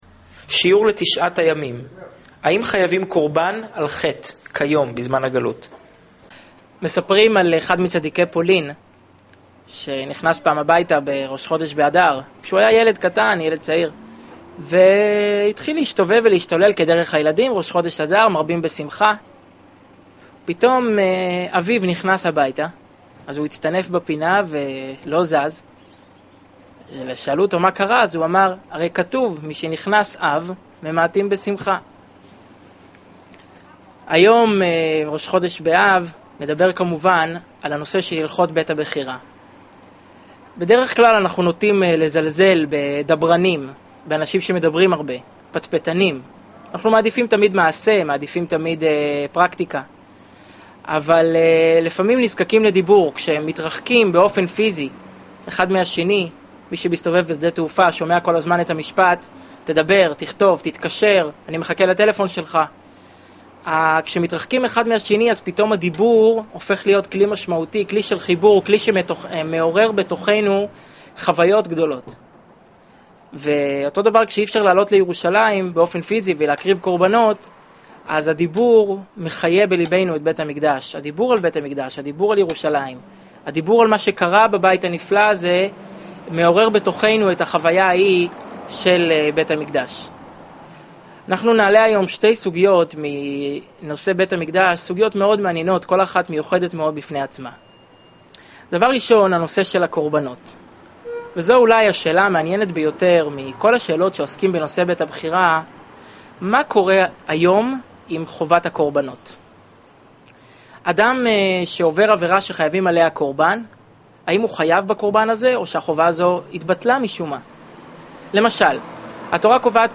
שיעור לתשעת הימים
שנמסר בביהכנ"ס חב"ד בראשל"צ